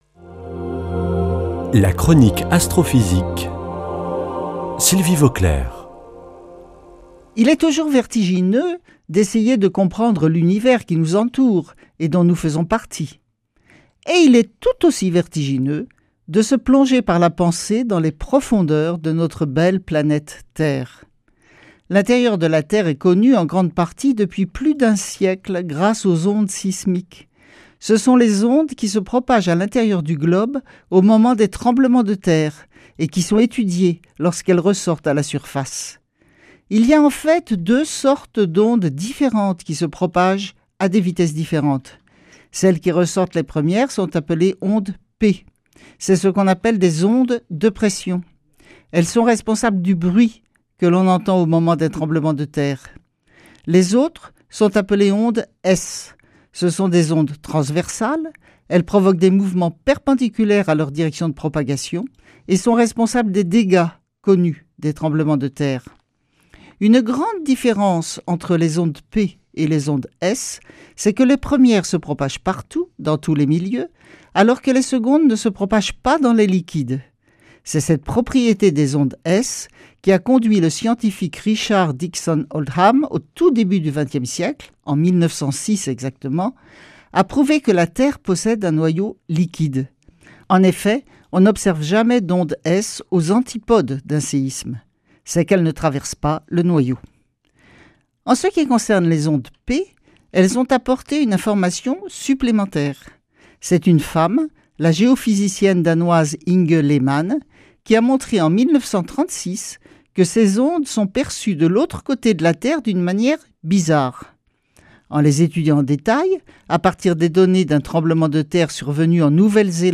[ Rediffusion ]
Une émission présentée par
Sylvie Vauclair
Astrophysicienne